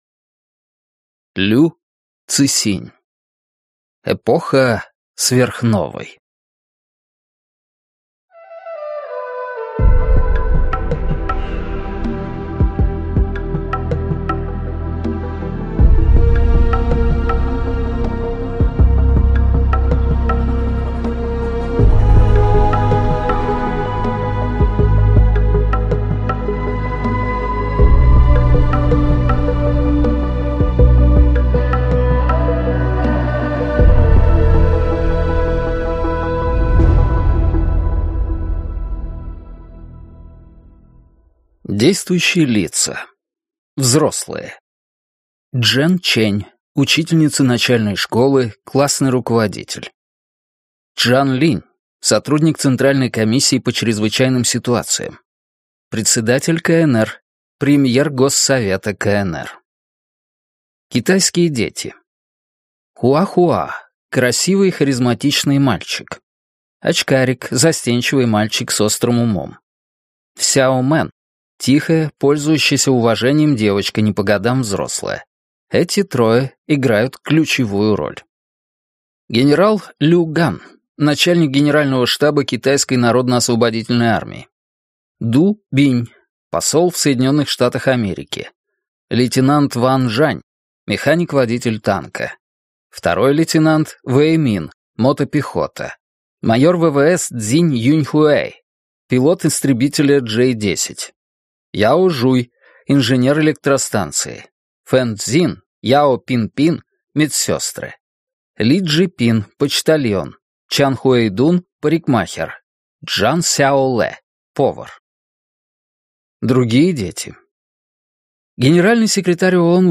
Аудиокнига Эпоха сверхновой | Библиотека аудиокниг